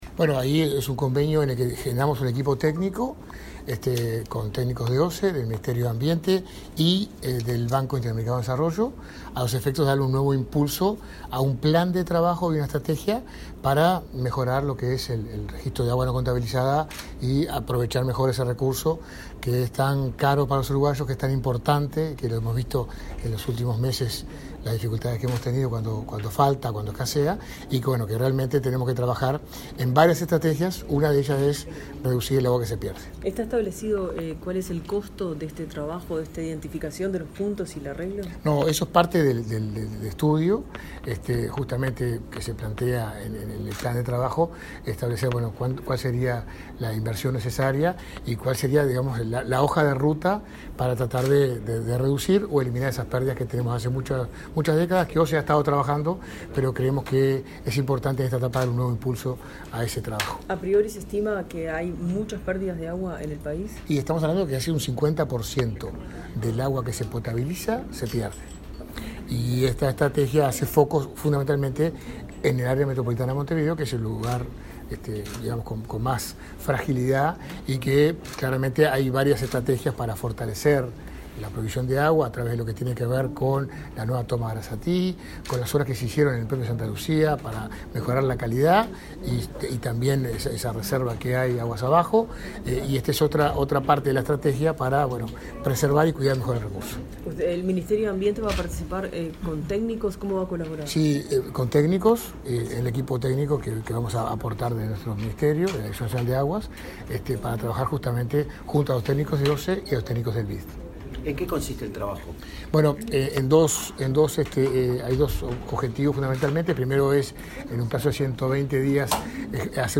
Declaraciones del subsecretario de Ambiente, Gerardo Amarilla
Declaraciones del subsecretario de Ambiente, Gerardo Amarilla 27/10/2023 Compartir Facebook X Copiar enlace WhatsApp LinkedIn El presidente de OSE, Raúl Montero, y el subsecretario de Ambiente, Gerardo Amarilla, firmaron un convenio de alcance nacional, para elaborar en forma conjunta una estrategia de reducción de agua no contabilizada. Luego, el secretario de Estado dialogó con la prensa.